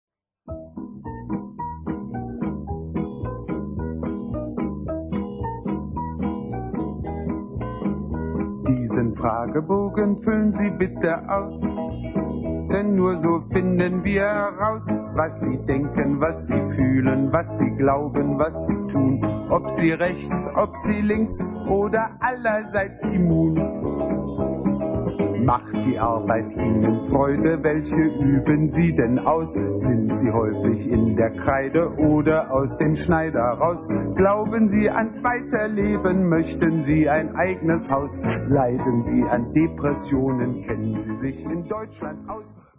Unter bearbeitet wurde das Rauschen entfernt.